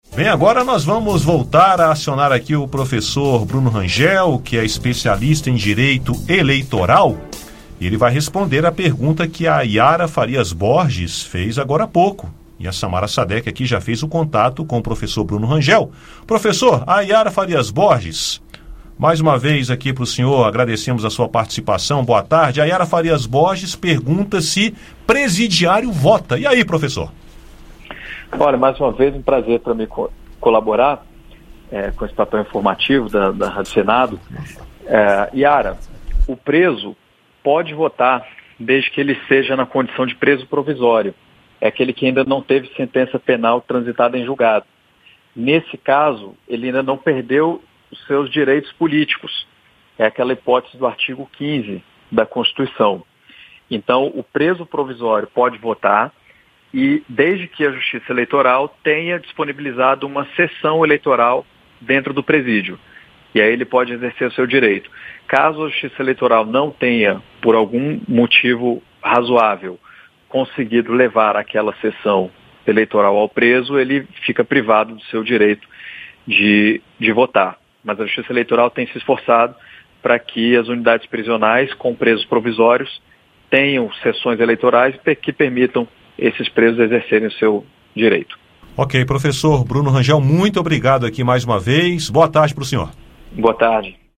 — Rádio Senado Entrevista Dúvida do ouvinte: pessoas que estão presas podem votar?